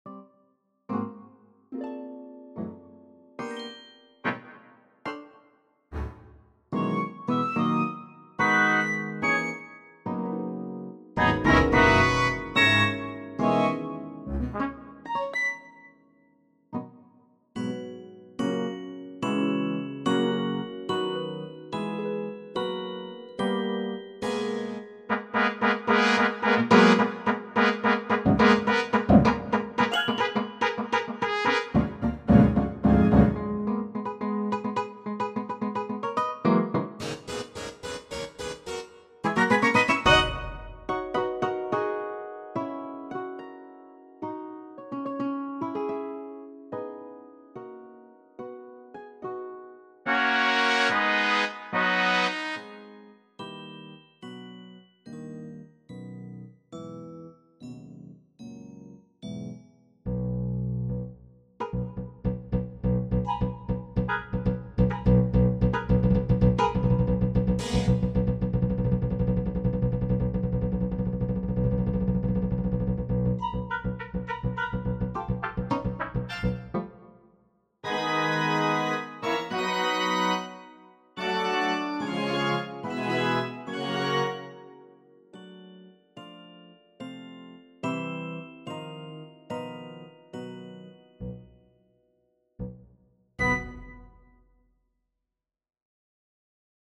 An old MIDI from the diary, with expanded instrumentation and converted to an MP3.